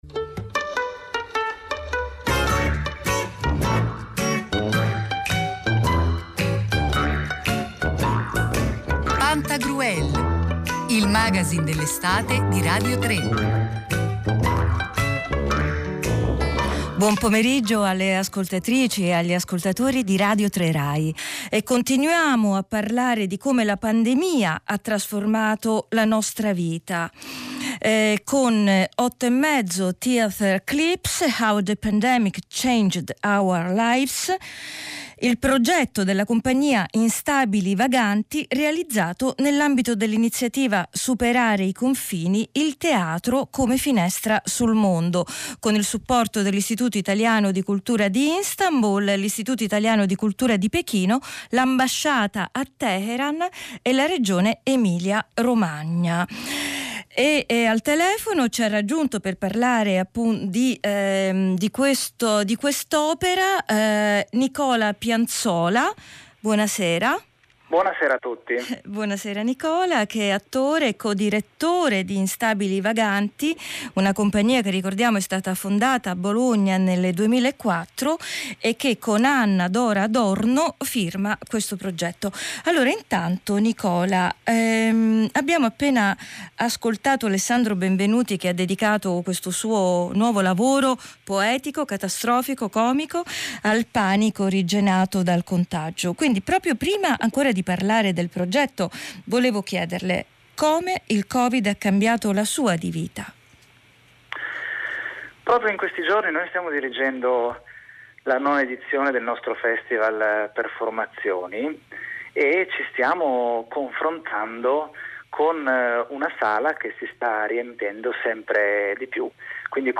Intervista su Radio 3 RAI a Pantagruel__05_09_2020